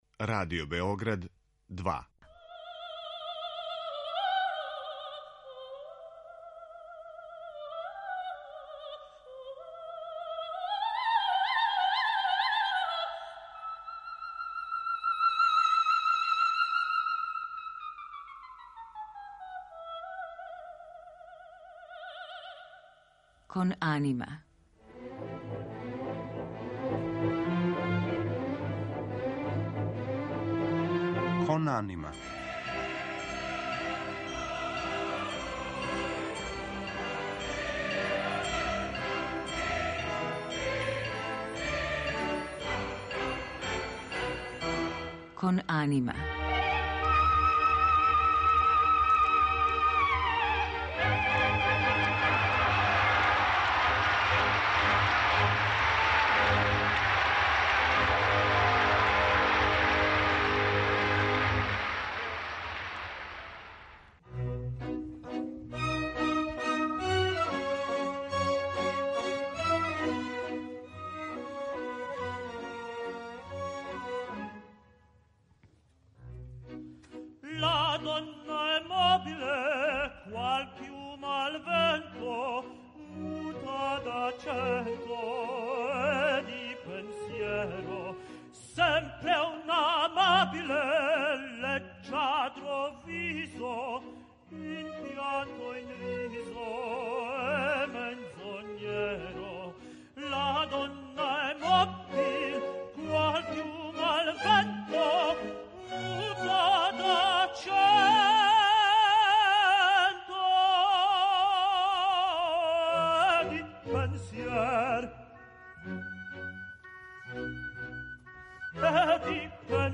Питања извођења опере на отвореном простору, затим избора улога и фаха, као и значаја сталног места у позоришту, само су неке од тема разговора у новом издању емисије која ће бити употпуњена одломцима из Вердијевих и Пучинијевих опера.